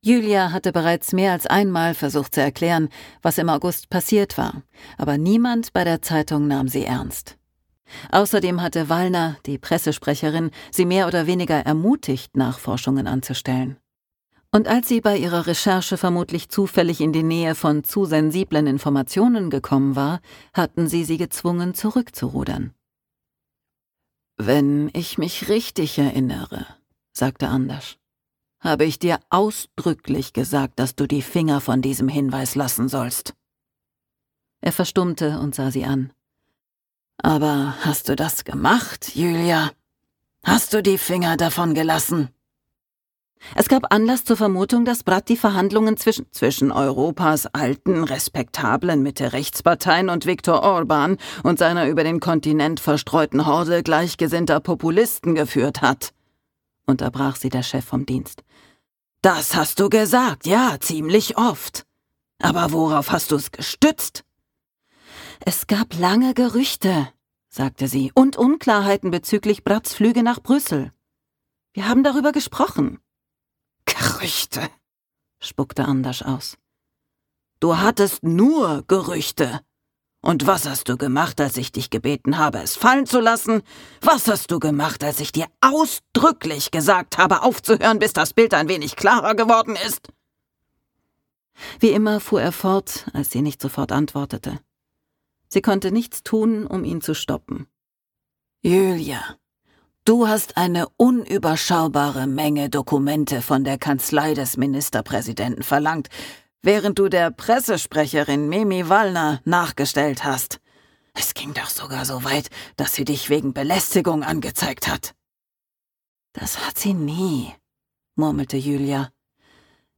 Ein spannendes Hörbuch über die Abgründe der Macht, die Rücksichtslosigkeit der Politik – und über eine Liebe, die auf eine harte Probe gestellt wird.
Gekürzt Autorisierte, d.h. von Autor:innen und / oder Verlagen freigegebene, bearbeitete Fassung.